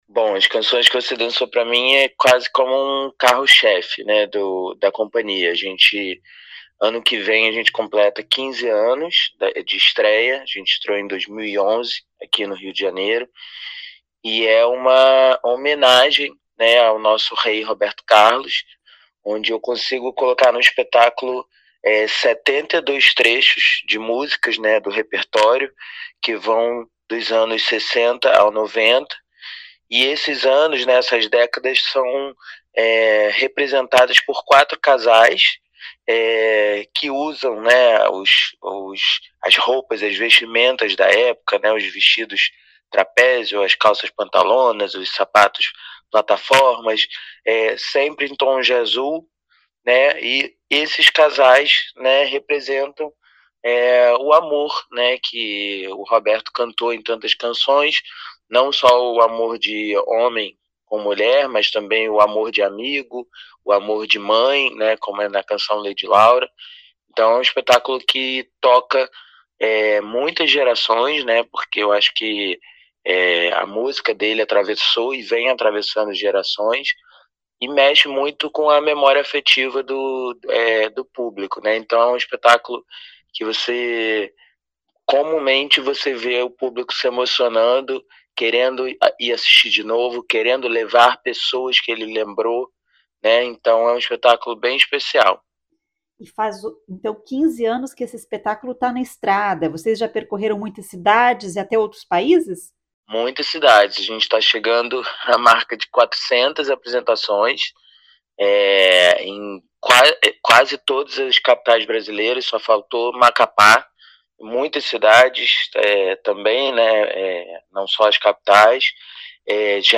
fala sobre os espetáculos.